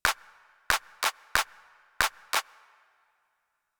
Here you will find rhythms with various types of notes in the bar.
One quarter note, two eighth notes, one quarter note, and two eighth notes.